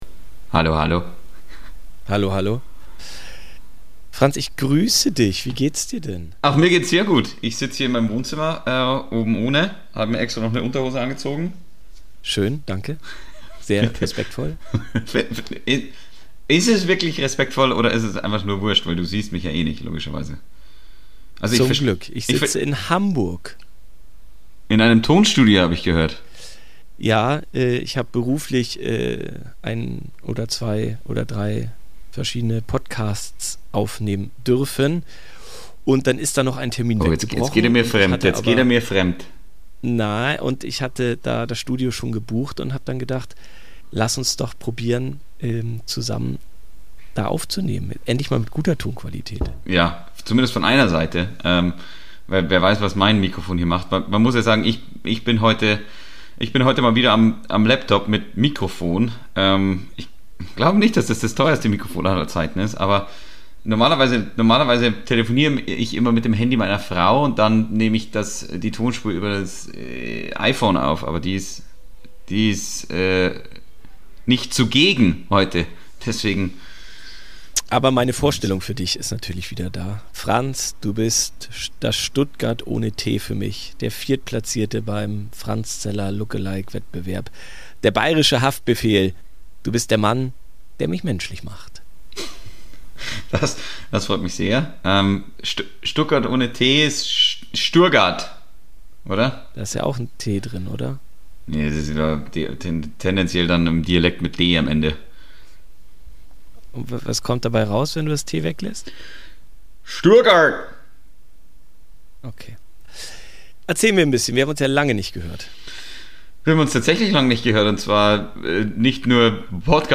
Immerhin ist der Ton so gut wie nie zuvor.